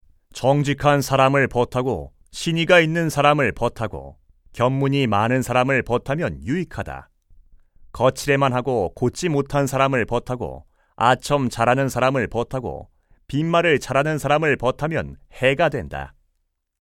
105쪽-내레이션.mp3